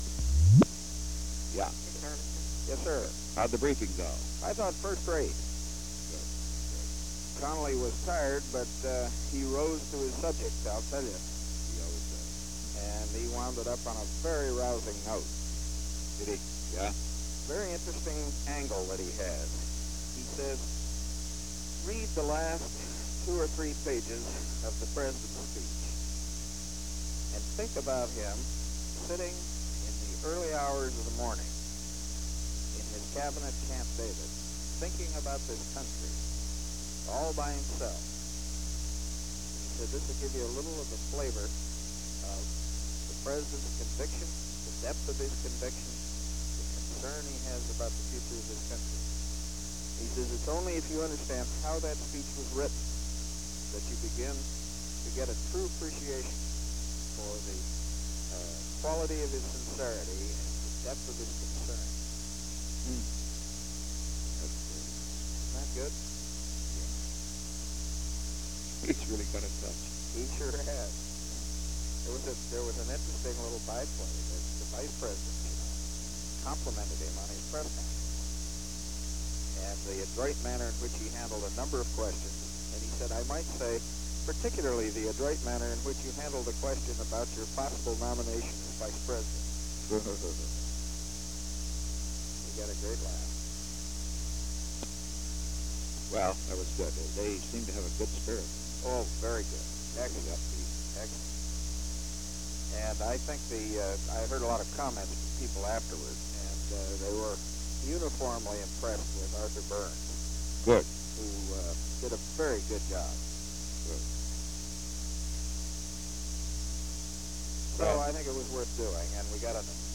Secret White House Tapes
Conversation No. 8-24
Location: White House Telephone
The President talked with John D. Ehrlichman.